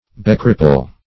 Search Result for " becripple" : The Collaborative International Dictionary of English v.0.48: Becripple \Be*crip"ple\, v. t. To make a cripple of; to cripple; to lame.